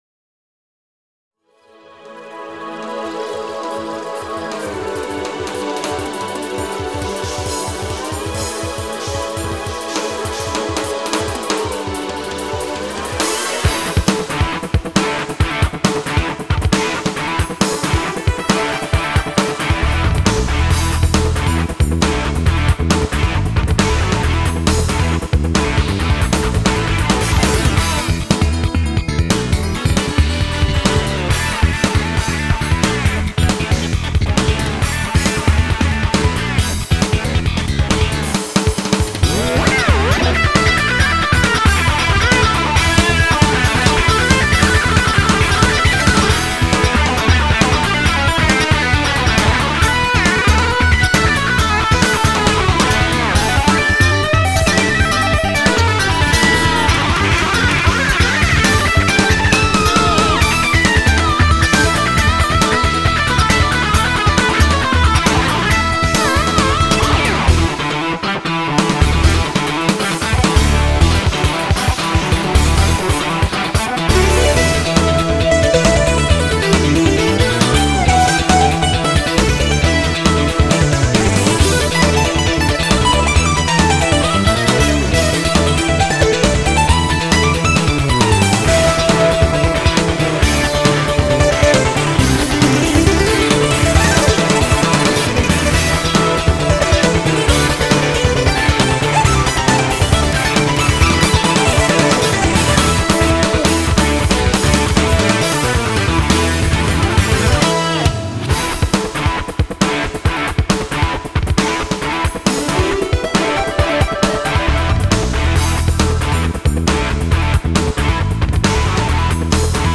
_ midi音源だけでこれだけいい感じなのができるとは…